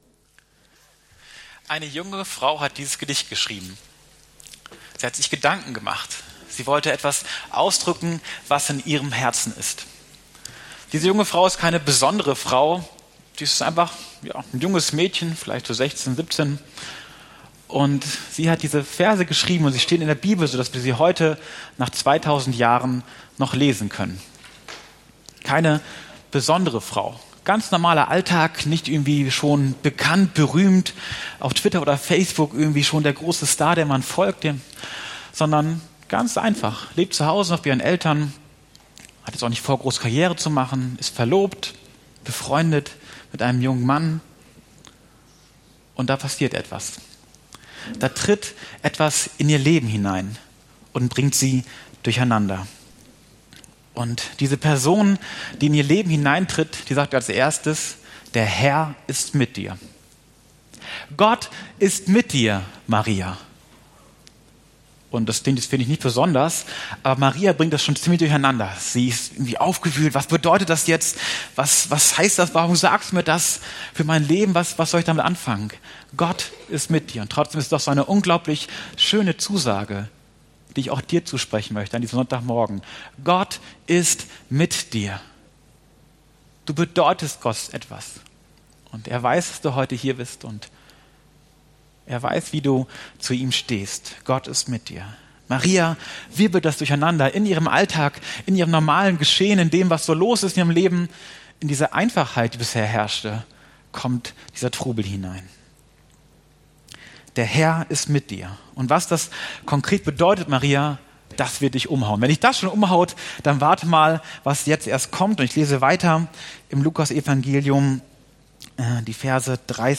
Anbetung = Freundschaft mit Gott – FeG Oldenburg